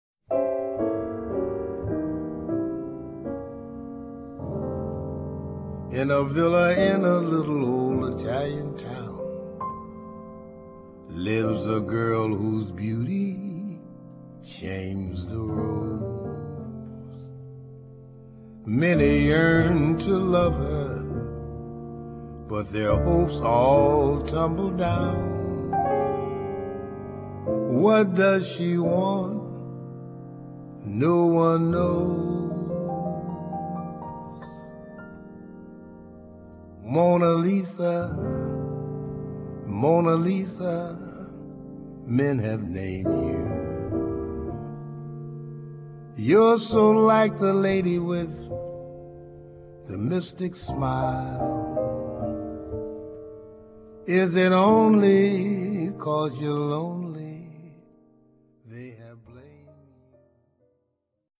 jazz standards
vocals, piano
tenor saxophone
guitar
bass
drums
trumpet
trombone